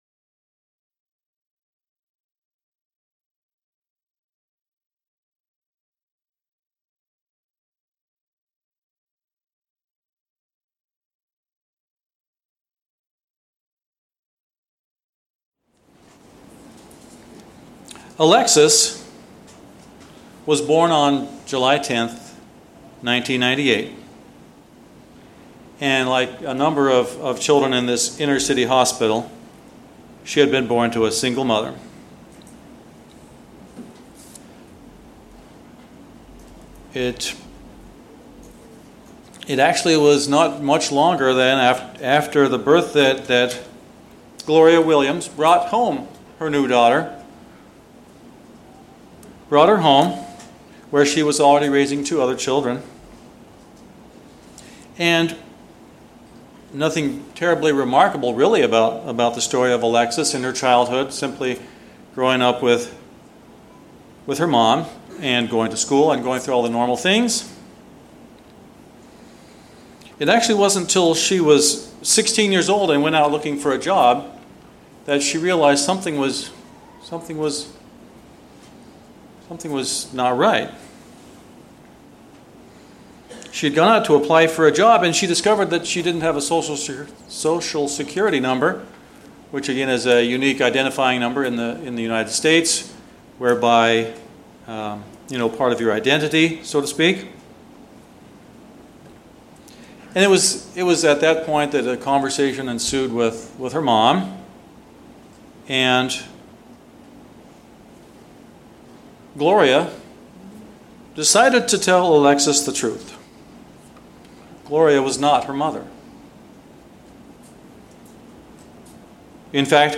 This sermon was given at the Osoyoos Lake, British Columbia 2019 Feast site.